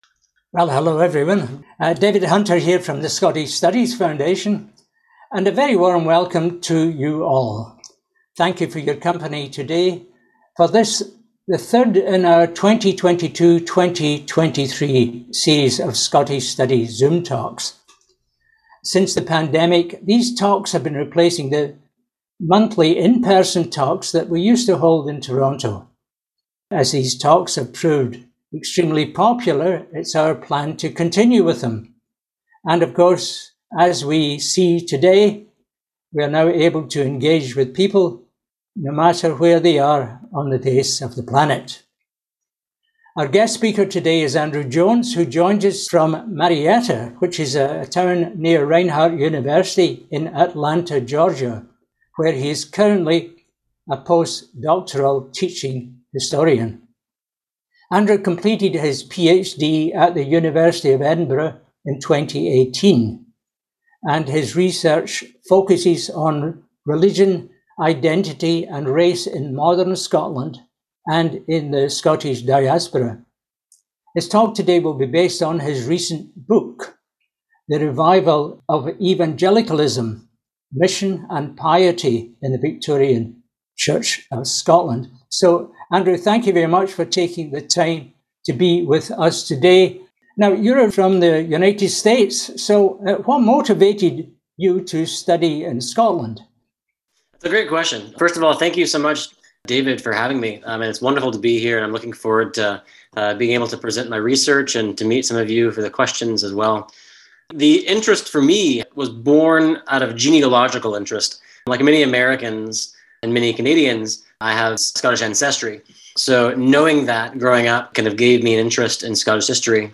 We are pleased to make the following audio recording of this talk available.